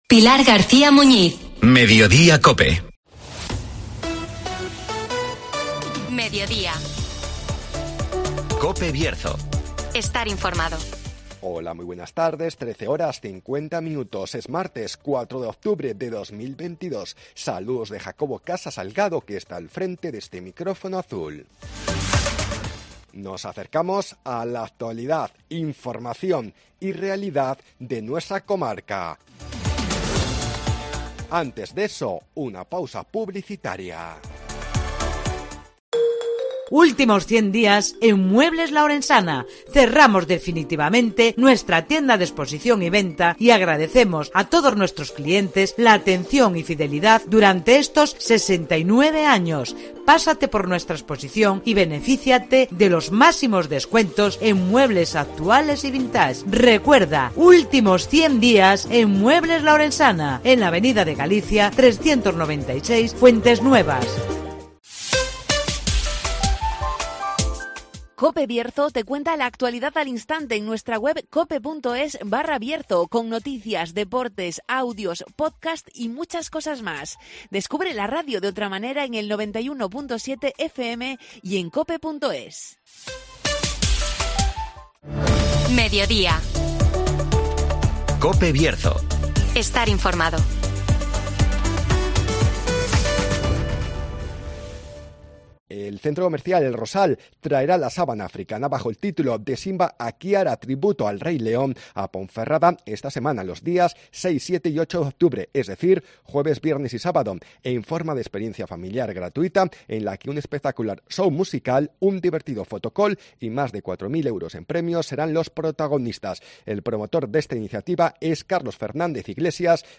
El Rosal acerca la sabana africana a Ponferrada con 'De Simba a Kiara. Tributo al Rey León' (Entrevista